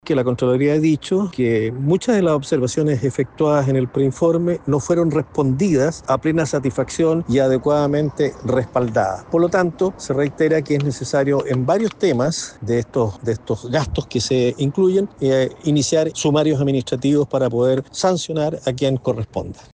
En tanto, el consejero regional (CORE) Osvaldo Urrutia (UDI) criticó la reiteración de prácticas que consideró inapropiadas para una administración pública y recalcó que debe sancionar a quien corresponda.